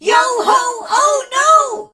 darryl_die_vo_03.ogg